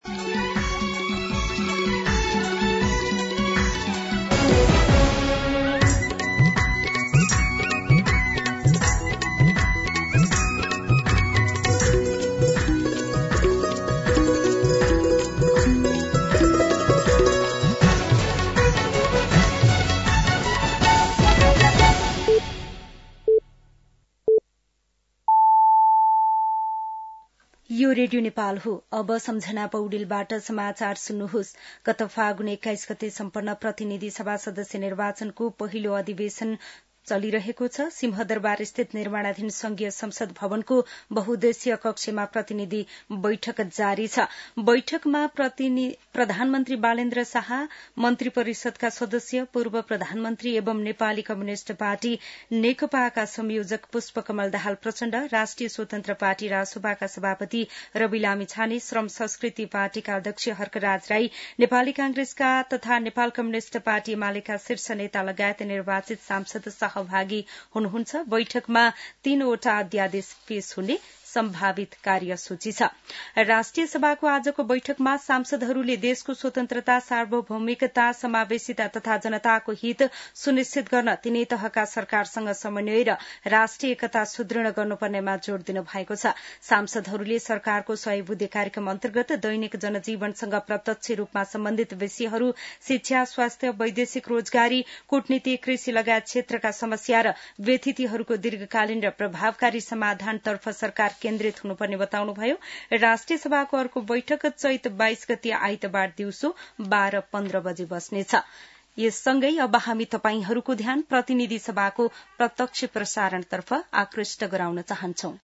दिउँसो ४ बजेको नेपाली समाचार : १९ चैत , २०८२
4-pm-News-12-19.mp3